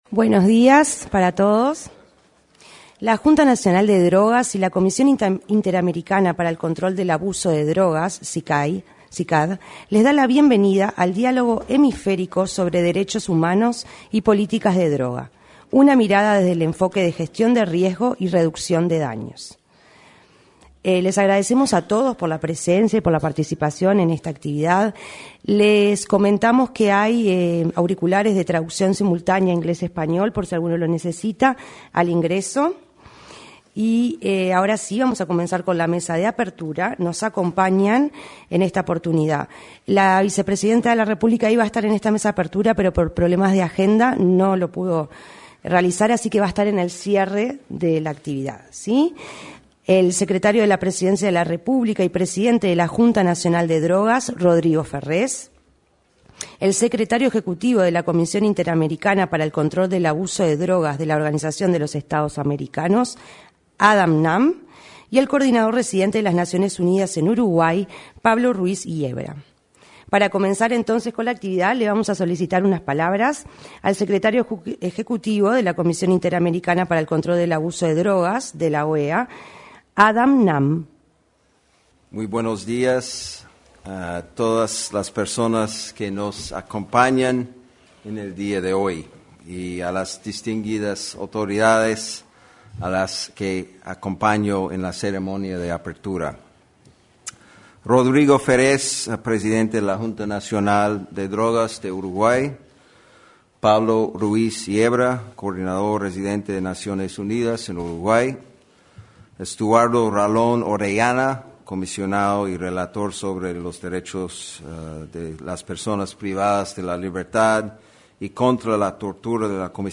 Este lunes 23, se realizó, en el salón de actos de la Torre Ejecutiva, el evento Diálogo Hemisférico sobre Derechos Humanos y Políticas de Drogas.